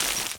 glass_i2.wav